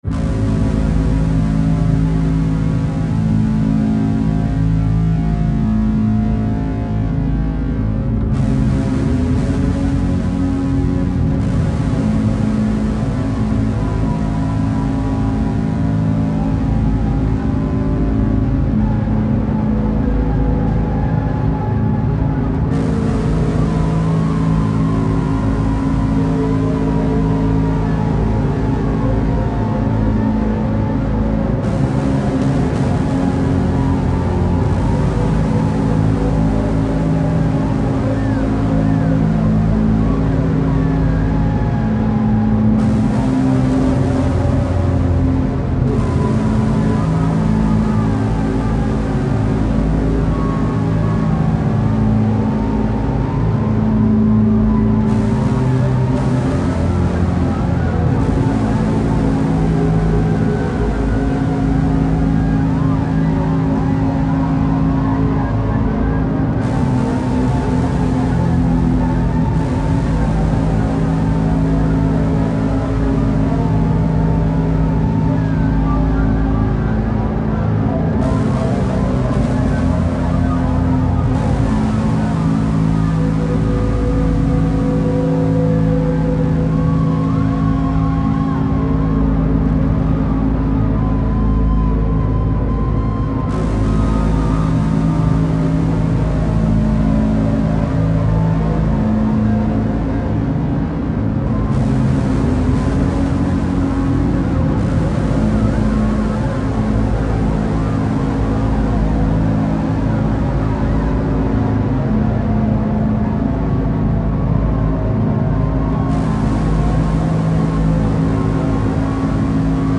Missouri focusing on various forms of extreme music and art.
experimental, industrial, ambient, or noise